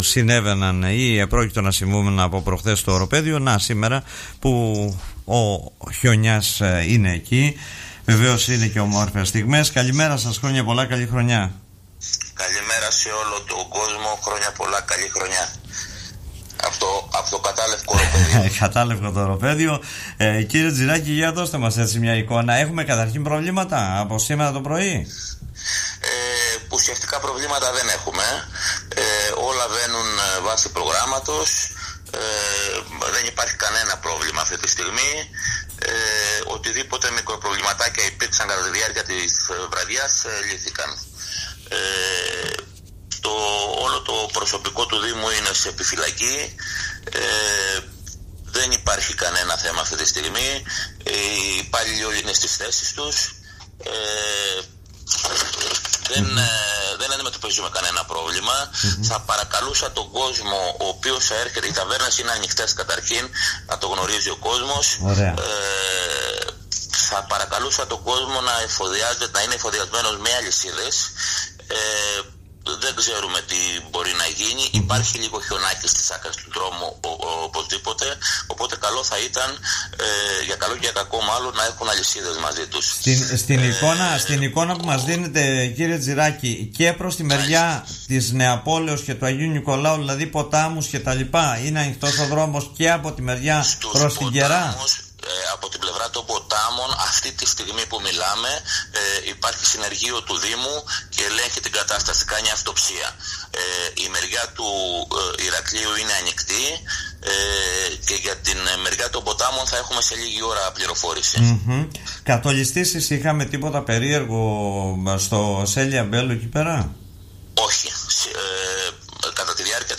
Ο Αντιδήμαρχος Οροπεδίου Λασιθίου, αρμόδιος για ζητήματα Πολιτικής Προστασίας Δημήτρης Τζιράκης
Ακούμε τον κ. Τζιράκη: